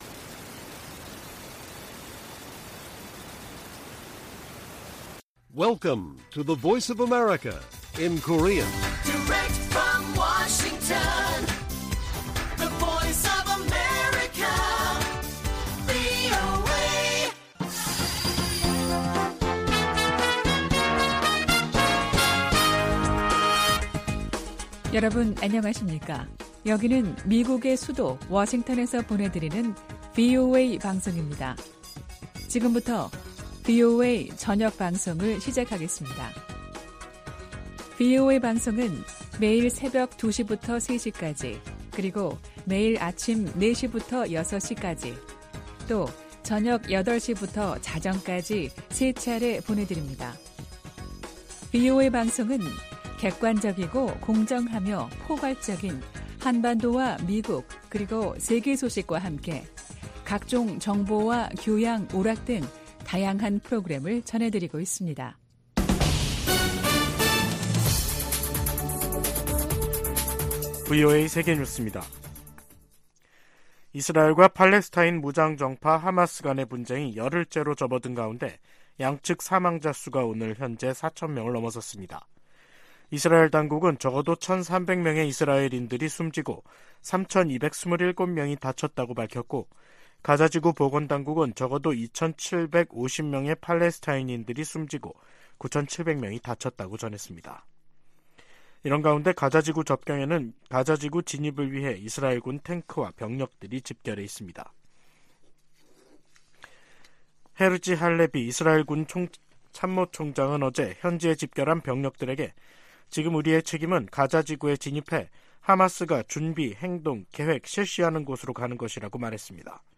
VOA 한국어 간판 뉴스 프로그램 '뉴스 투데이', 2023년 10월 16일 1부 방송입니다. 북한이 지난달 컨테이너 1천개 분량의 군사장비와 탄약을 러시아에 제공했다고 백악관이 밝혔습니다. 미국 정부가 북러 무기 거래 현장으로 지목한 항구에서 계속 선박과 컨테이너의 움직임이 포착되고 있습니다. 줄리 터너 미 국무부 북한인권특사가 한국을 방문해 북한 인권 상황을 개선하기 위해 국제사회가 힘을 합쳐야 한다고 강조했습니다.